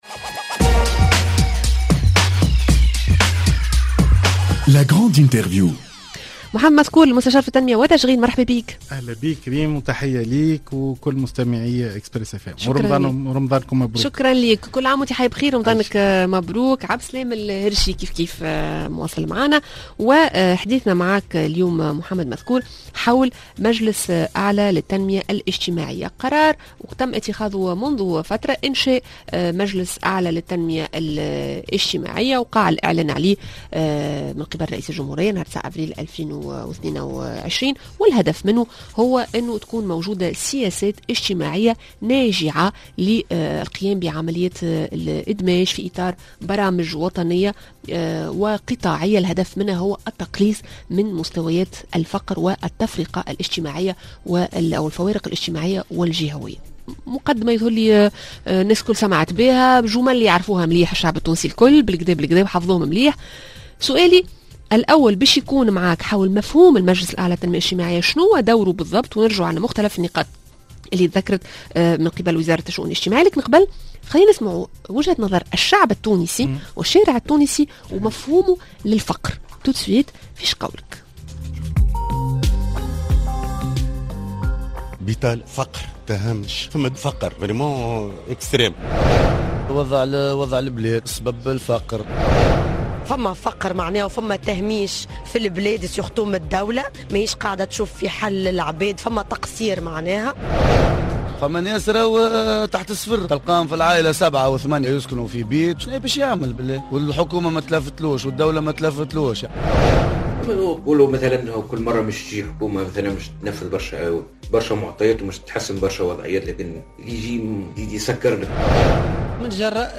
La grande interview: مجلس أعلى للتنمية الإجتماعية؟